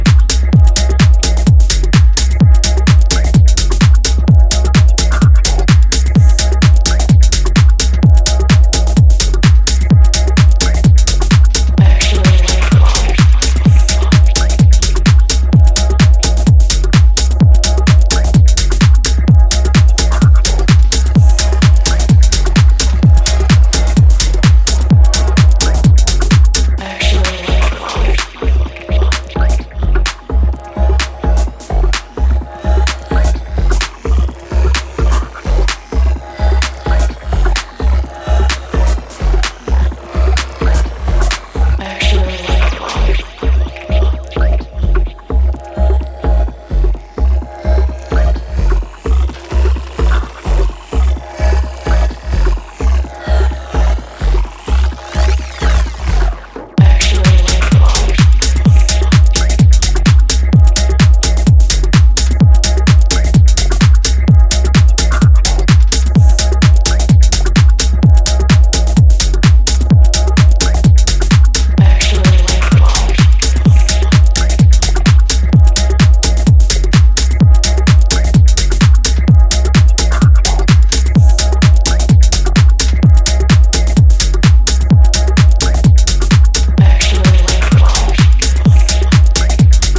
One of the UK’s most exciting house talents